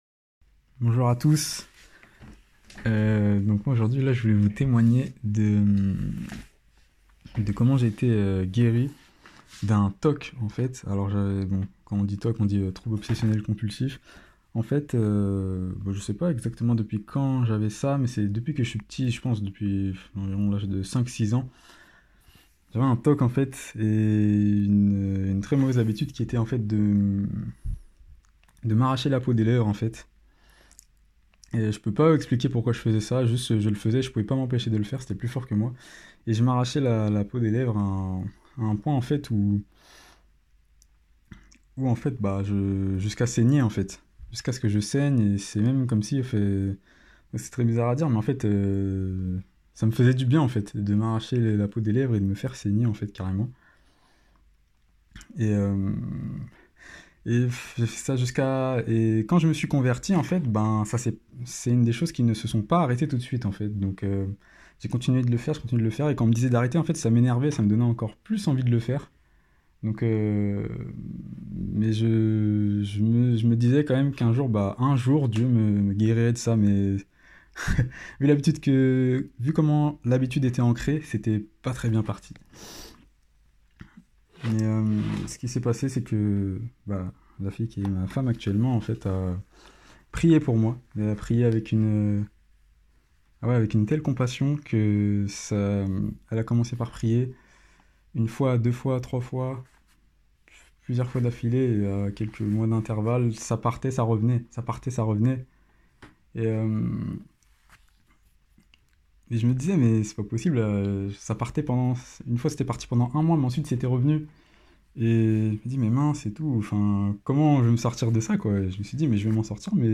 temoignage_toc.mp3